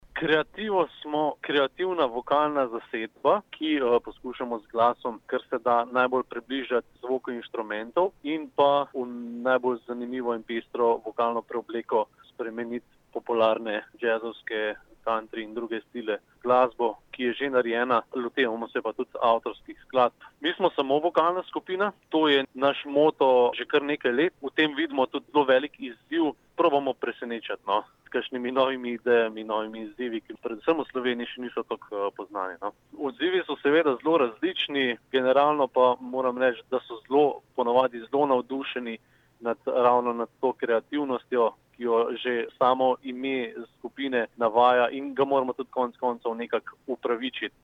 Izjavo